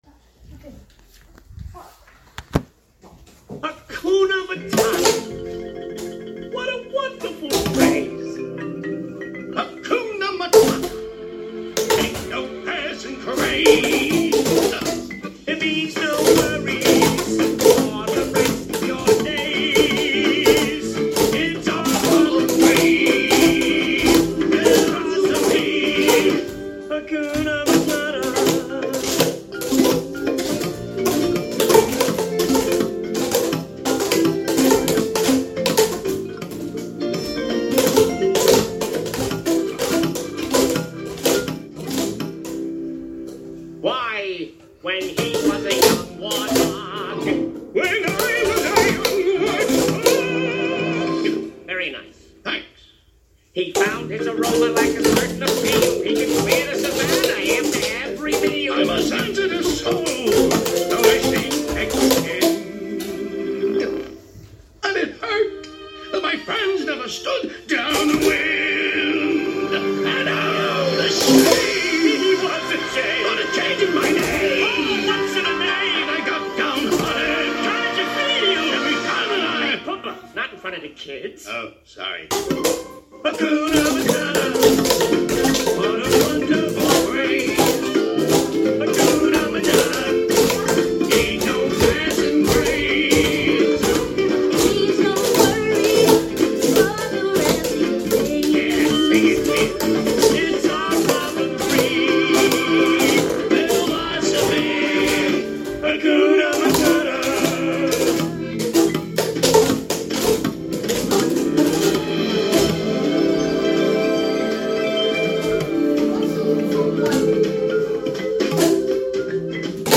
Hakuna Matata mit Boomwhackers
Seit Freitag, den 25.8.23, üben sich die Eulen nun im Musizieren mit Boomwhackers.